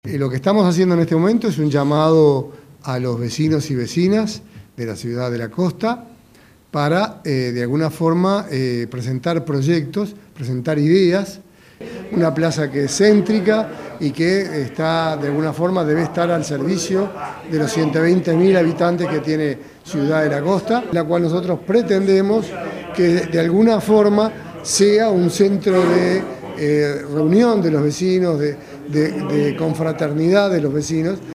Durante una conferencia de prensa en la sala de actos del municipio, la Alcaldesa, Mtra. Sonia Misirián, resaltó la importancia de este proyecto, que busca revitalizar este icónico espacio y promover la interacción entre diferentes generaciones, con un enfoque cultural y recreativo para la comunidad.